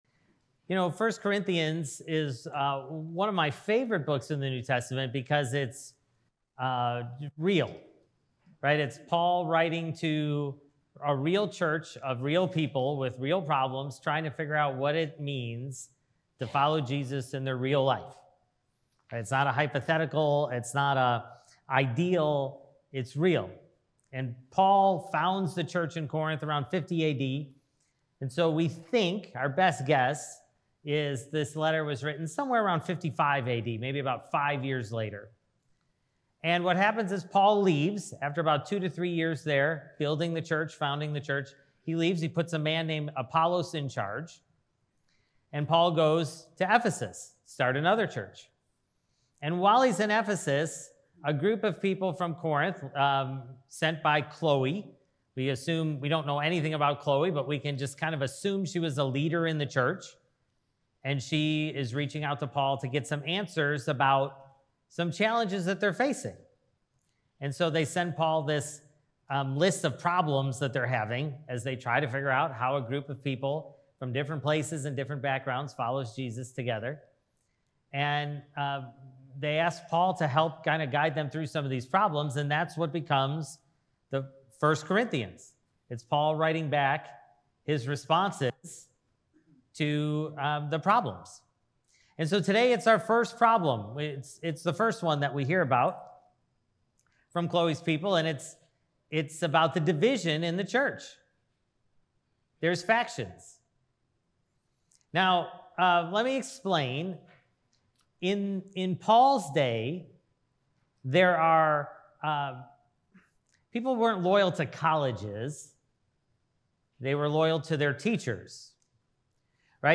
Sermon: Only Works If Jesus Is First (1 Corinthians 1:10-18)